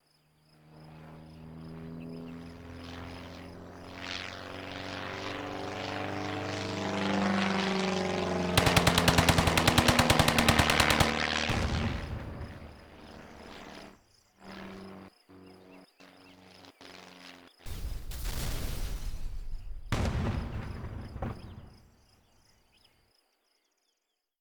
Caída de caza de la Primera Guerra Mundial con disparos (Albatros)
avión
disparo
metralleta